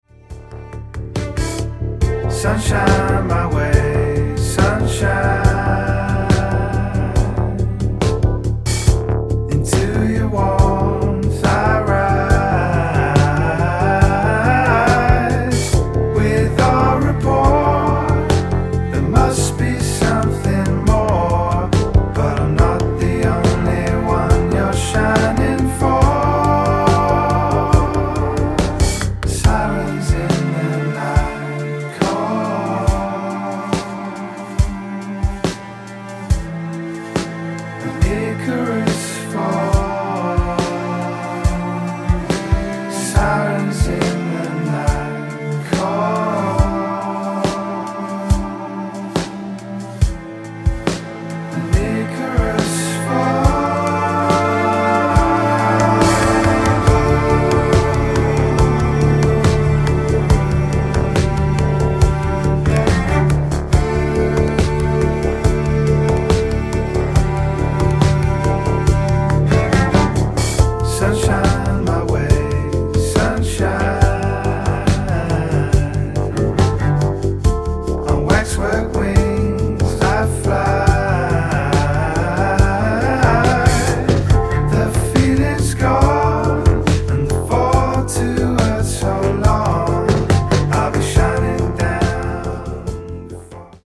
It's a male vocal